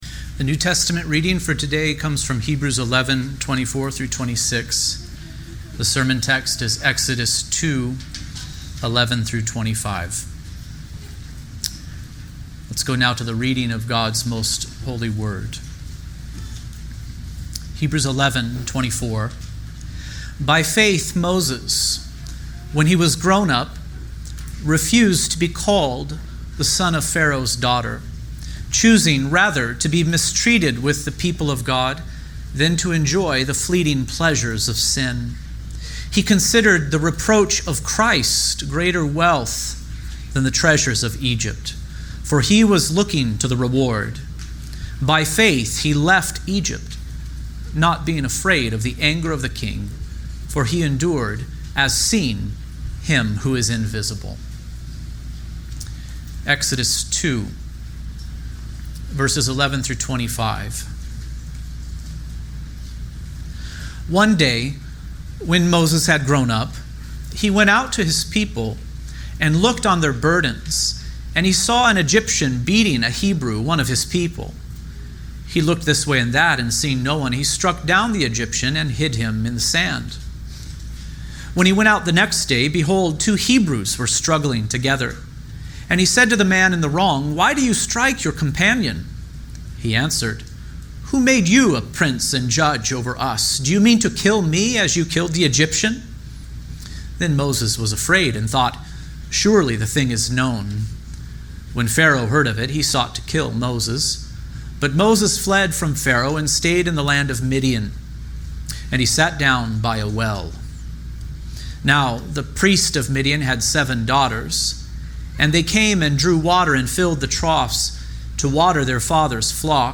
He Was Looking To The Reward | SermonAudio Broadcaster is Live View the Live Stream Share this sermon Disabled by adblocker Copy URL Copied!